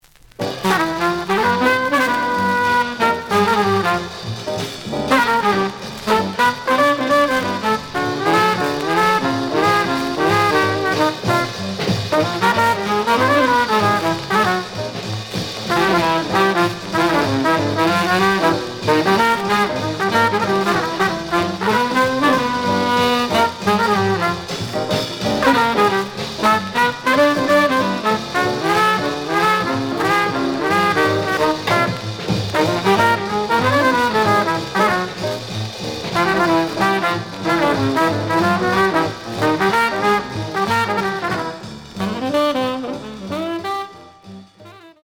The audio sample is recorded from the actual item.
●Genre: Bop